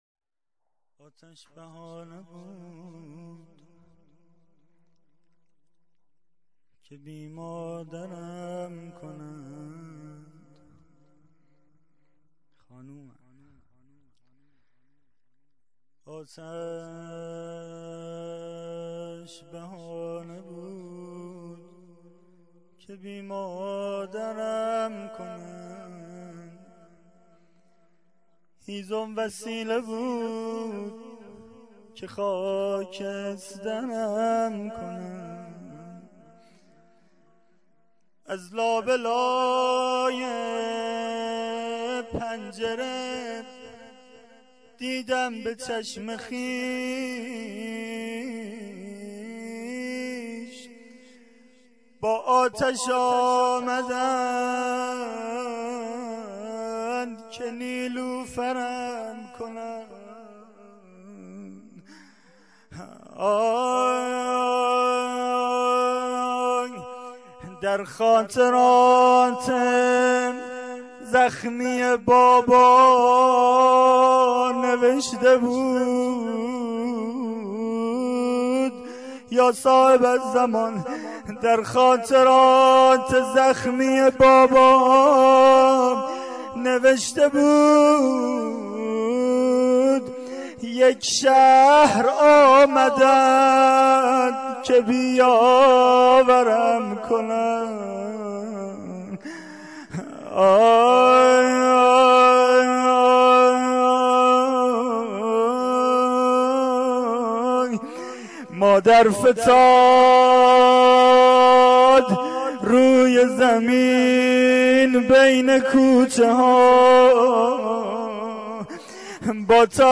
rozeh-akhar.mp3